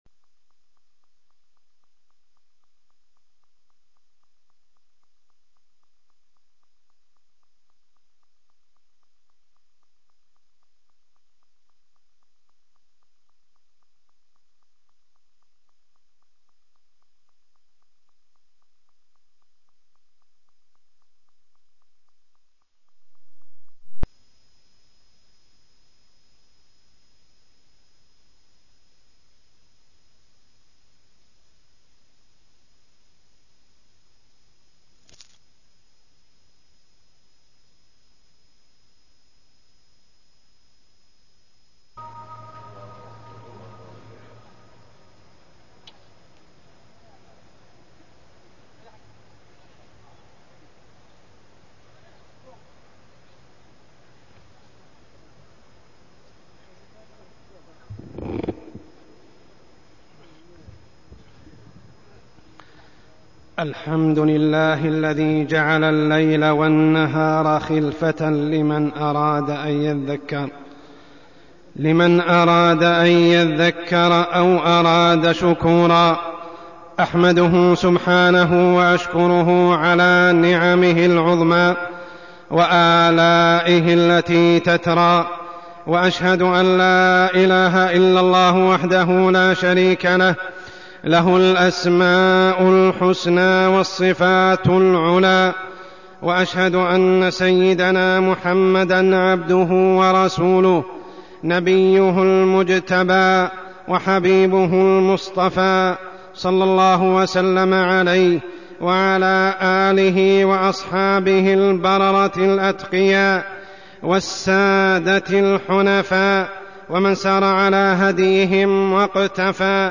تاريخ النشر ٢٢ صفر ١٤١٨ هـ المكان: المسجد الحرام الشيخ: عمر السبيل عمر السبيل أهمية الوقت